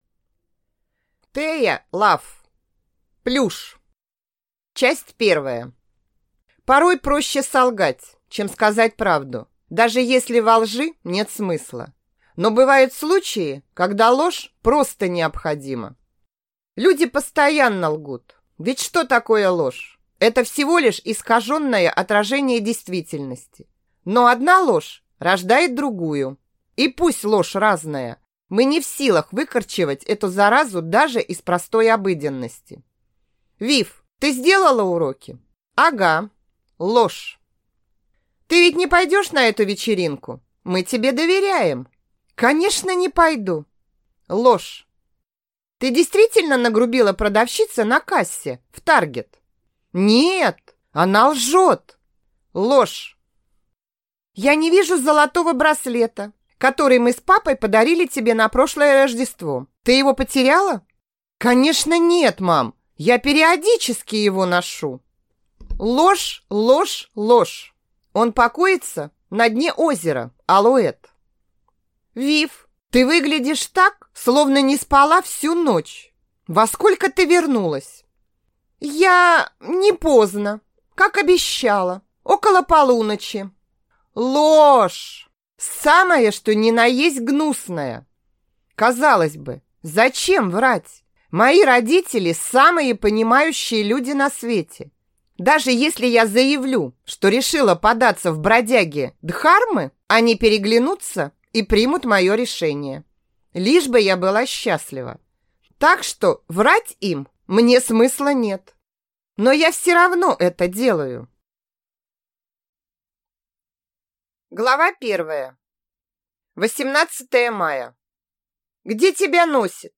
Аудиокнига Плюш | Библиотека аудиокниг